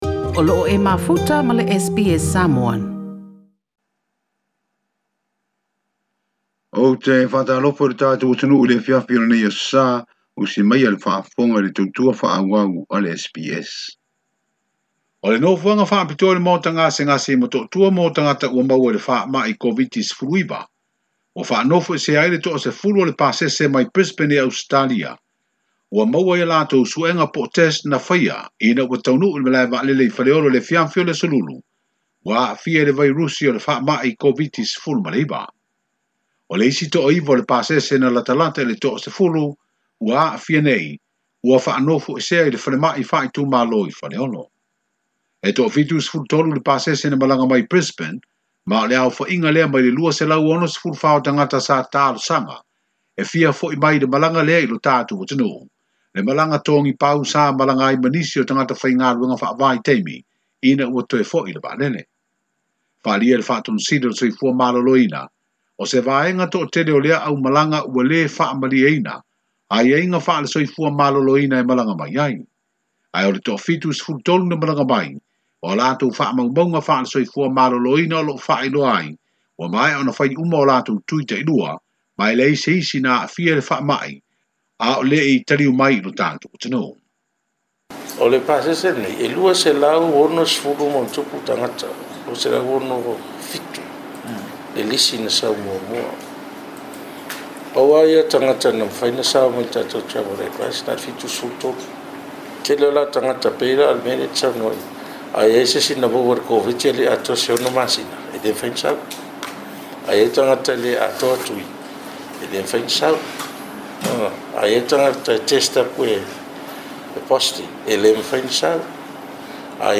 O loo aofia ai ma se faamatalaga mai le palemia, Fiame Naomi Mataafa, i le faamalosia o Sa ma Vavao mo le 48 itula.